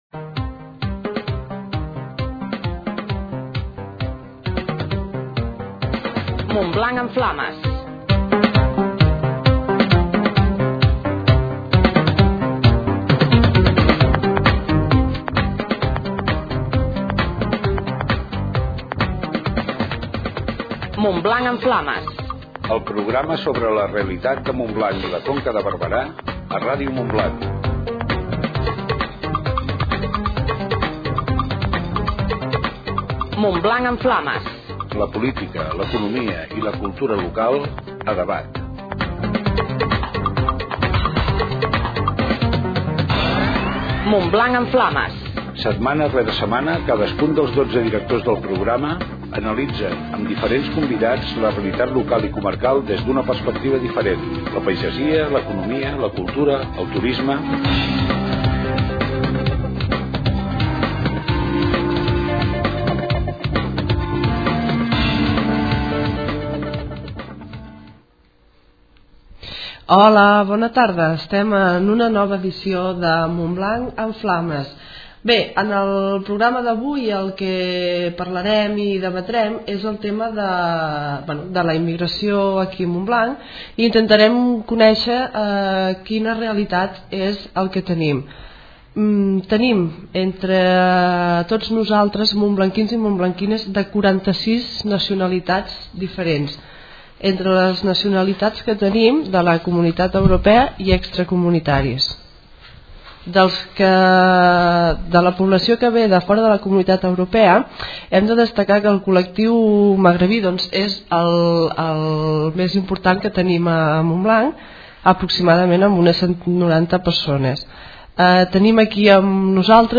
Escolta l’entrevista del 7º programa Montblanc en Flames
Escolta-lentrevista-del-7º-programa-Montblanc-en-Flames.mp3